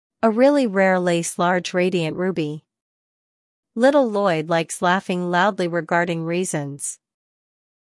Step 3: The /r/ and /l/ Sounds
Game: Tongue Twisters.
tongue-twister-R-L-IPA.mp3